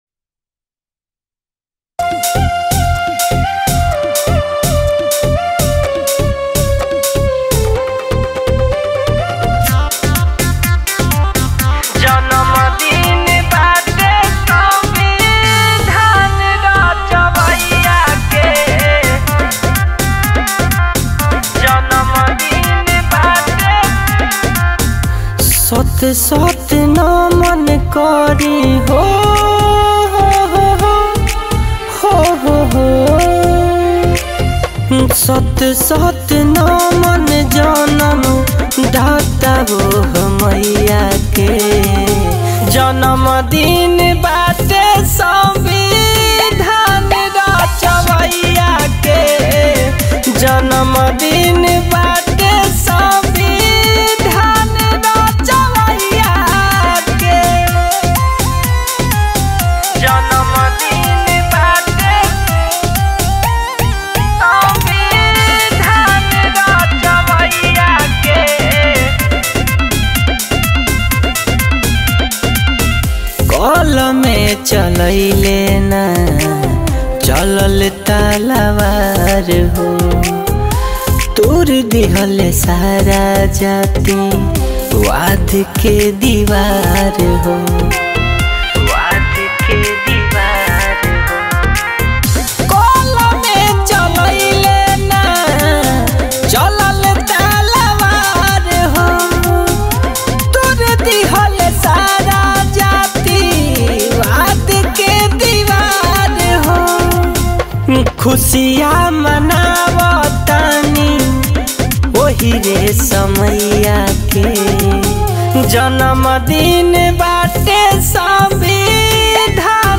Releted Files Of Bhojpuri Mp3 Song